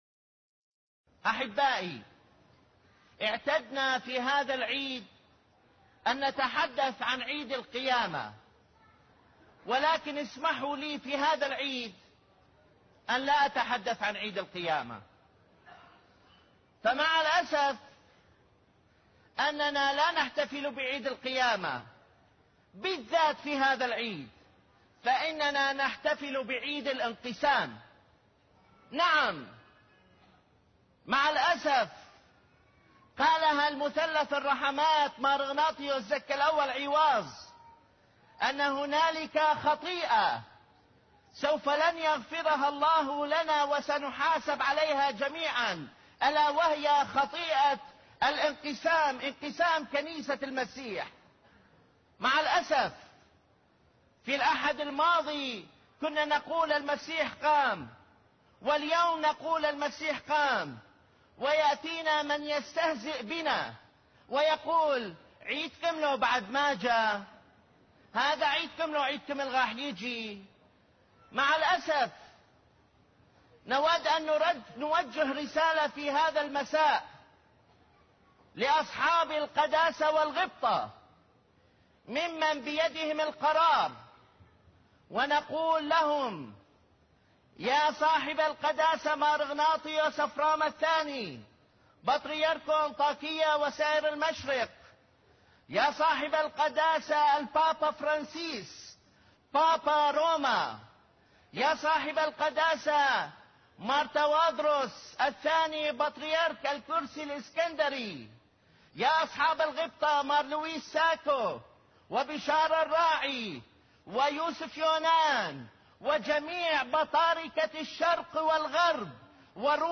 الجدير بالذكر، انّ عظة المطران نيقوديموس داود، ليست في هذا العام..